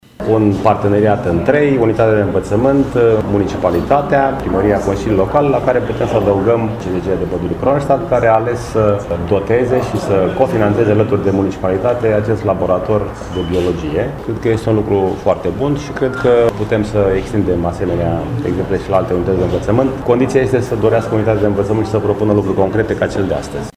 Laboratorul a fost inaugurat în prezența primarului George Scripcaru, care a spus: